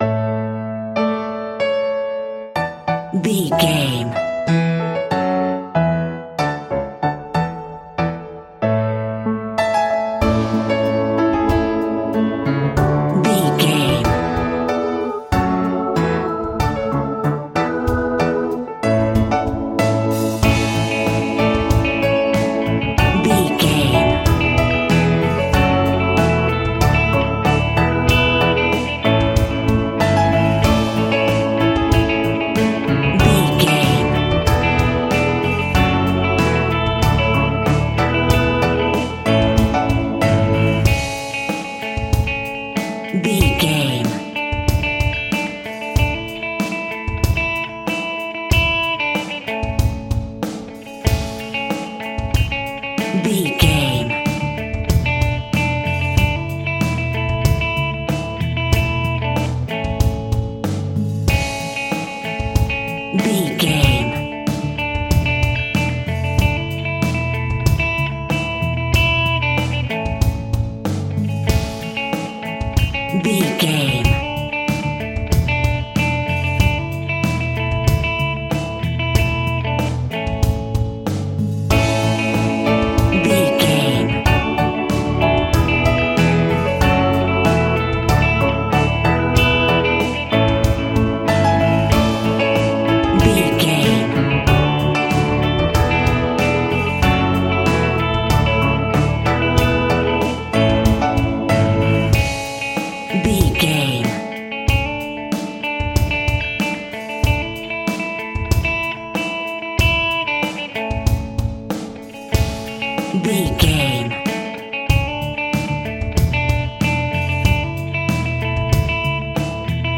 Uplifting
Ionian/Major